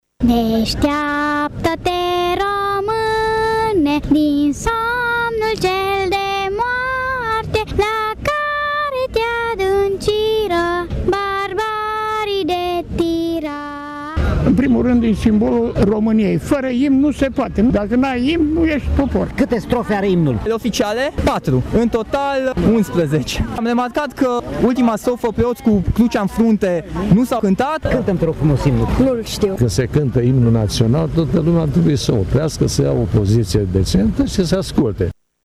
Nu toți târgumureșenii știau cine a compus imnul țării sau câte strofe are, dar au fost impresionaţi de ceremonie:
vali-voxuri-imn.mp3